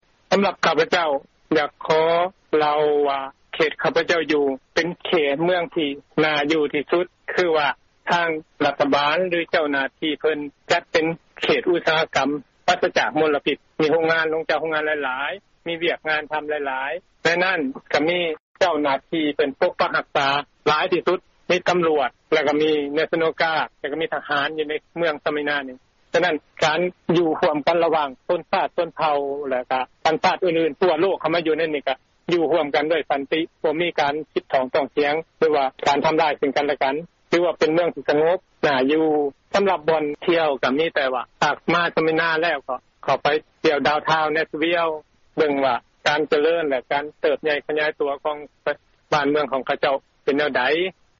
ທີ່ທ່ານຫາກໍໄດ້ຮັບຟັງຜ່ານໄປນັ້ນ ແມ່ນການໂອ້ລົມກັບຄອບຄົວຄົນລາວ ໃນເຂດເມືອງສເມີນາ ລັດເທັນເນັສຊີ.